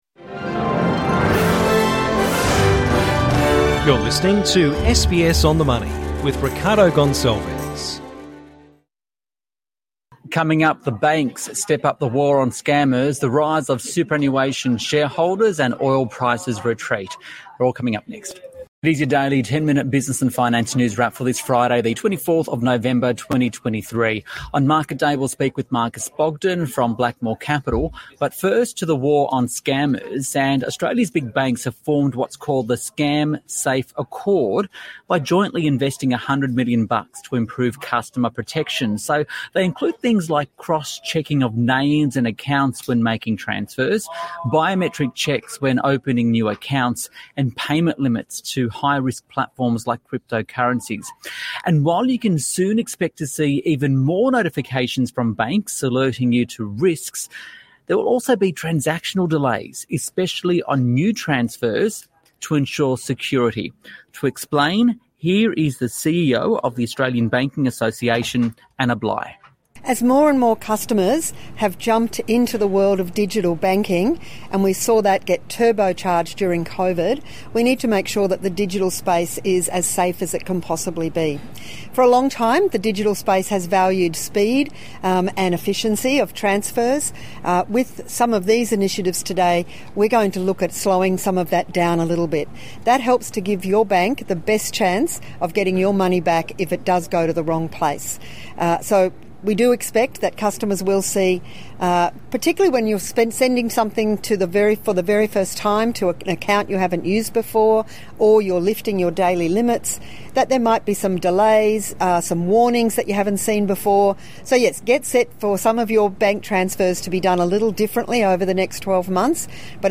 Australian banks are coming together to invest $100m in an effort to improve customer protections against scammers. The Australian Banking Association's Anna Bligh explains why that may mean longer transfer times for some transactions